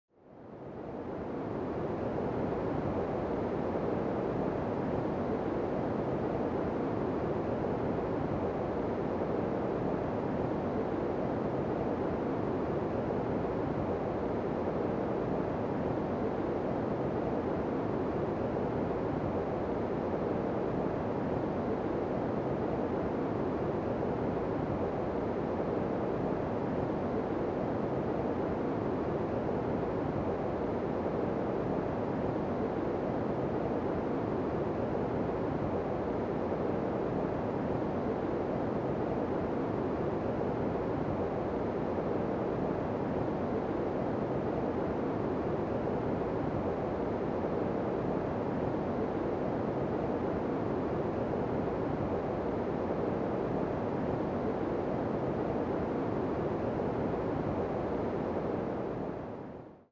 Waves that sing you to sound effects free download By calm 3233 Downloads 2 months ago 59 seconds calm Sound Effects About Waves that sing you to Mp3 Sound Effect Waves that sing you to sleep. Let the ocean’s hush quiet your thoughts. 🌊💙 Calm’s white noise tracks help you sleep deeper and dream bigger.